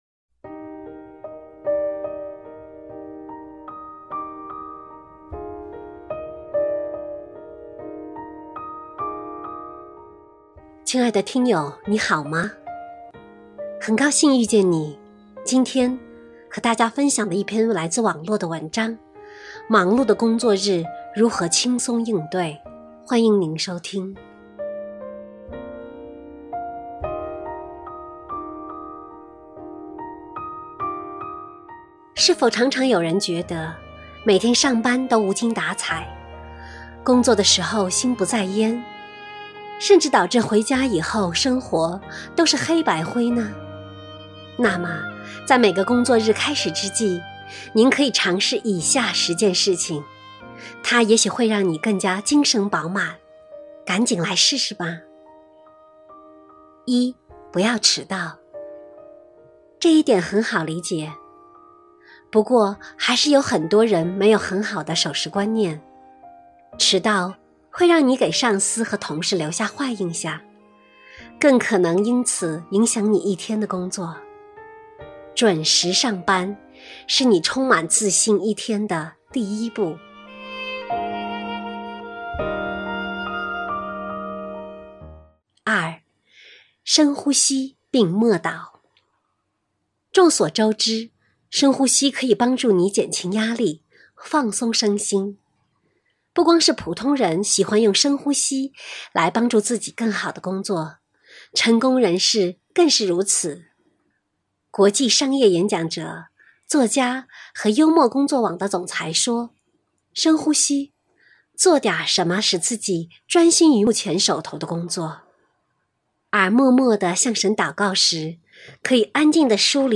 首页 > 有声书 | 灵性生活 > 忙碌的工作日如何轻松应对